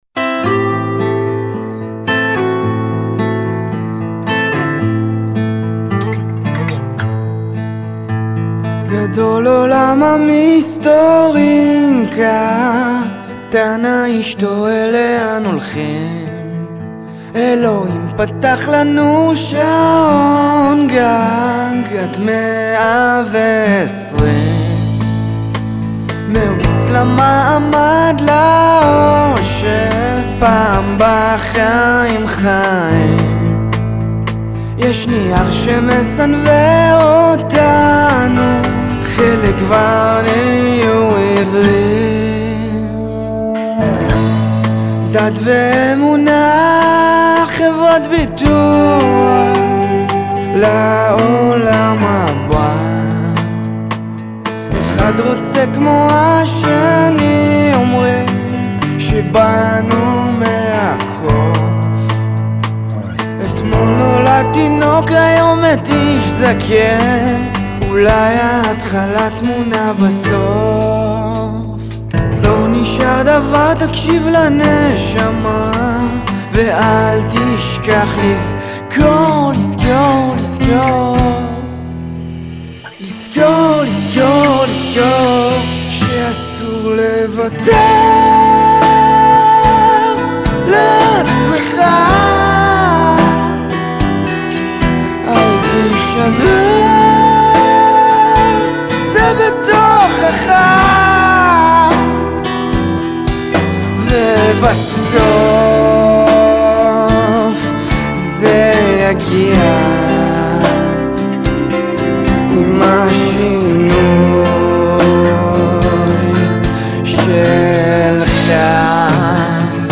אולפן הקלטות בראשון לציון